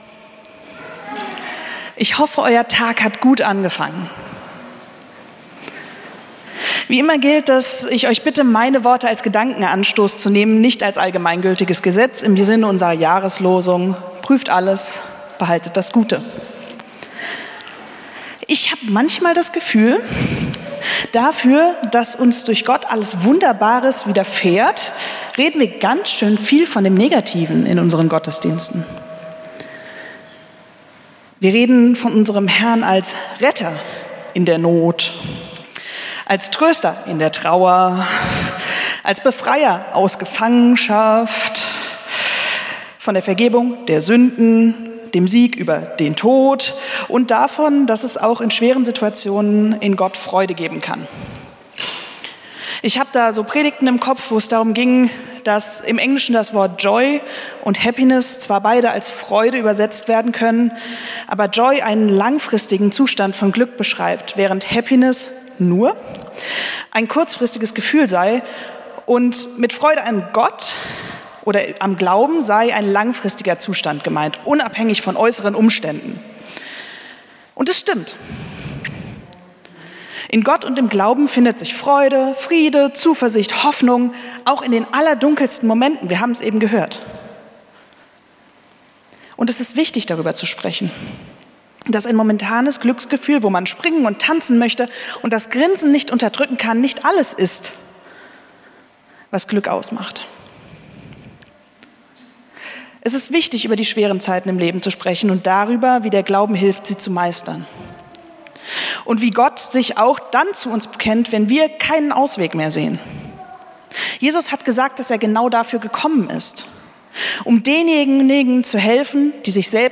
Predigt vom 24.08.2025